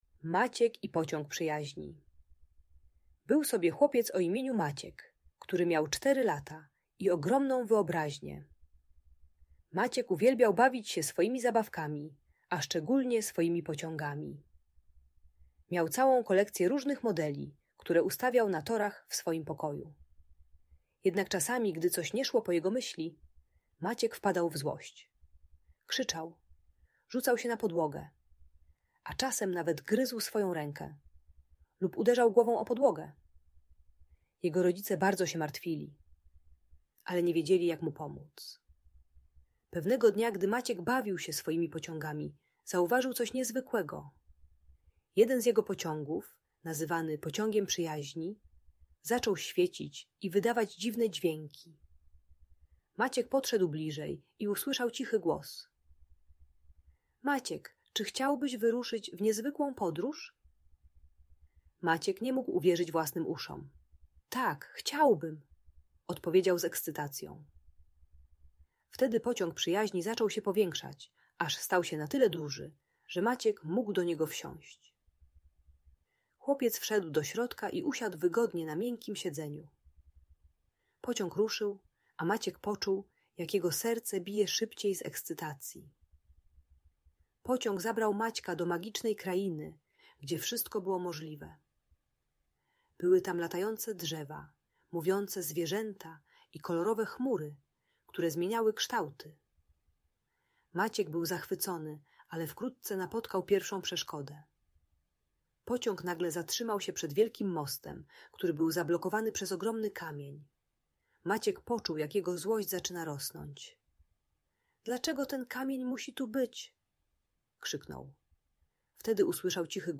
Maciek i Pociąg Przyjaźni - Bunt i wybuchy złości | Audiobajka